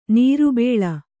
♪ nīru bēḷa